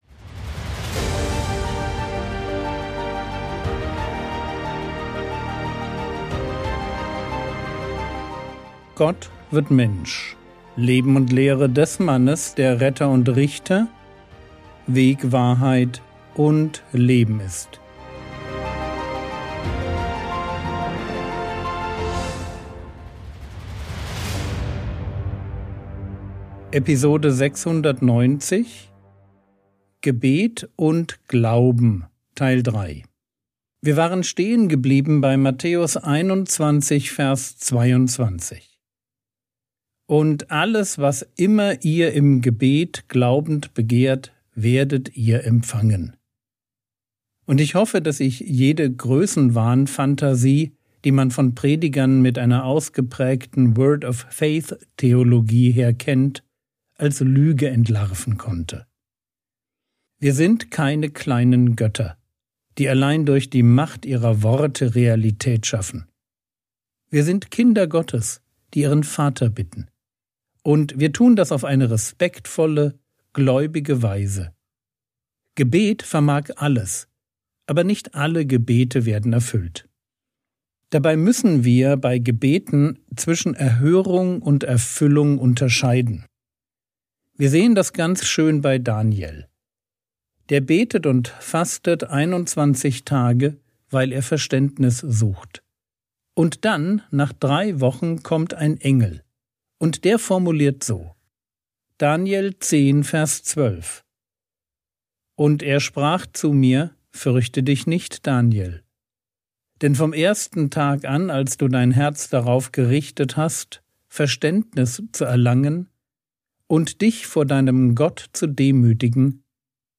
Episode 690 | Jesu Leben und Lehre ~ Frogwords Mini-Predigt Podcast